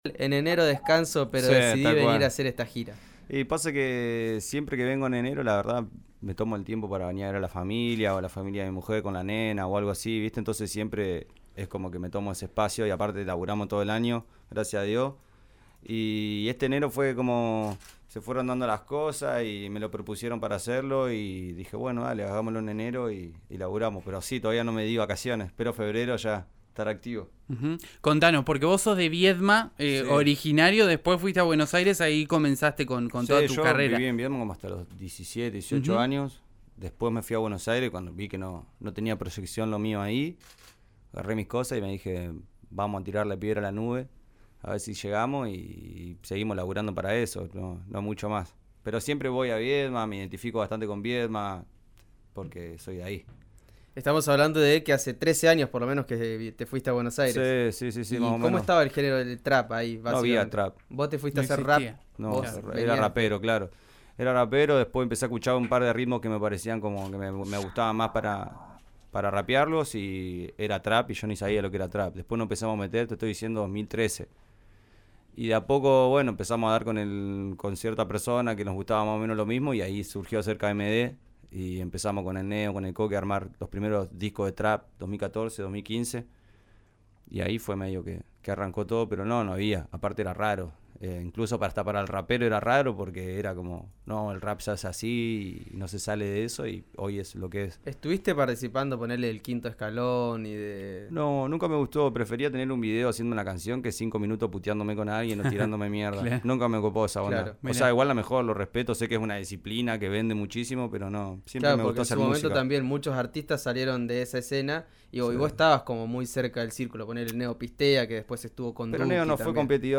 En esta ocasión, el oriundo de Viedma visitó el estudio de RIO NEGRO RADIO para contar sobre su carrera a una década de su primer hit.